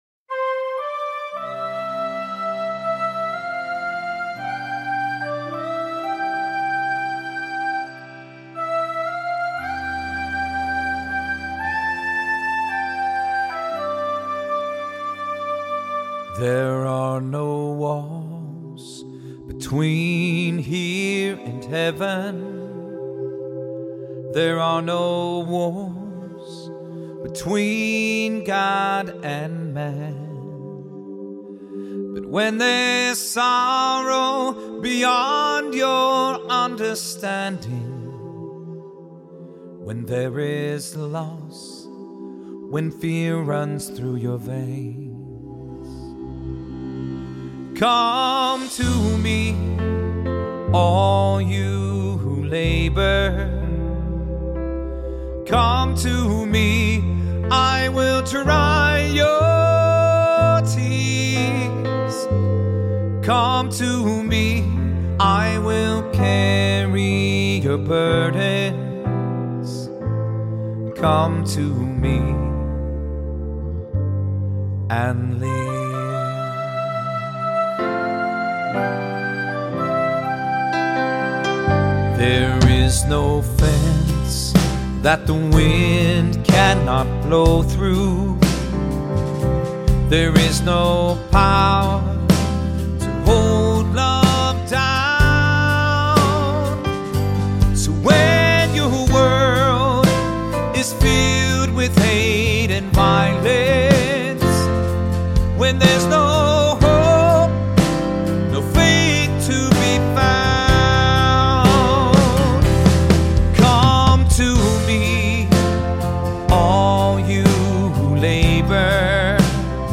Voicing: Soloist or Soloists